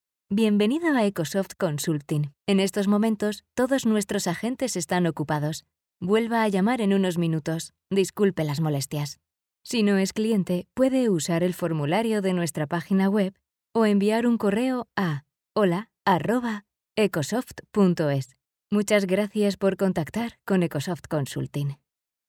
Kommerziell, Zugänglich, Vielseitig, Warm, Sanft
Telefonie